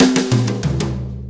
Brushes Fill 69-06.wav